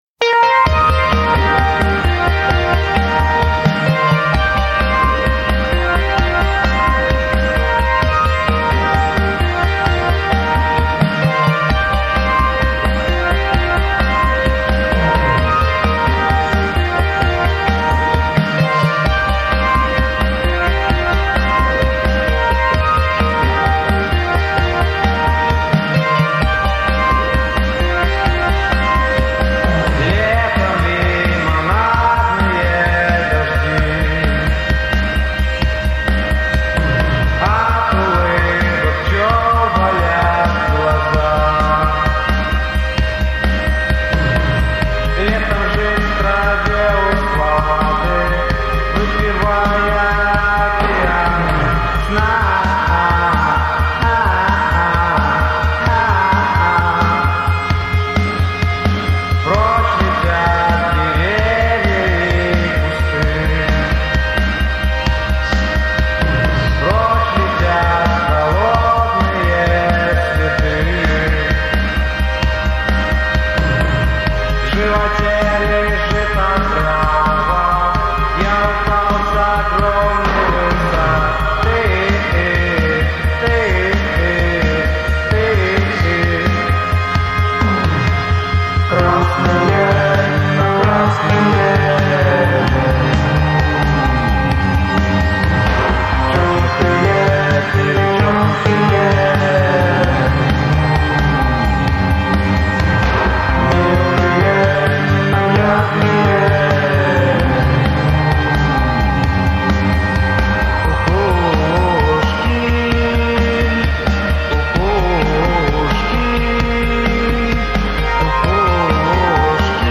альт-поп-проекта